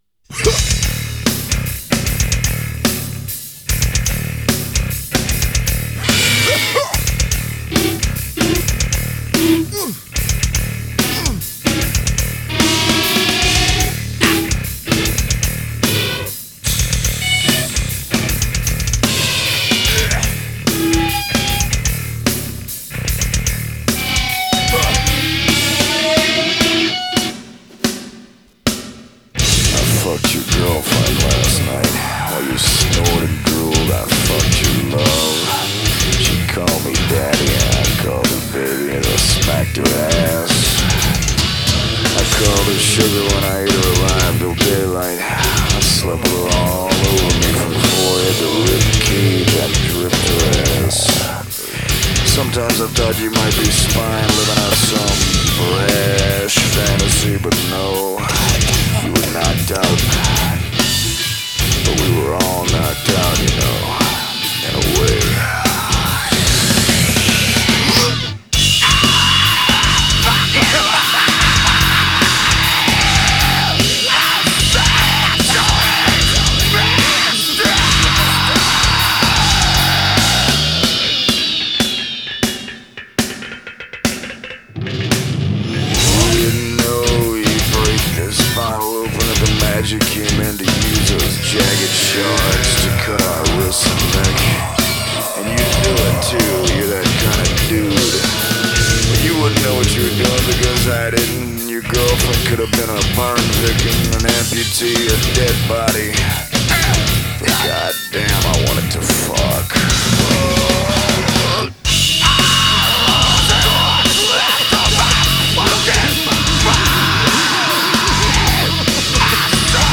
Groove metal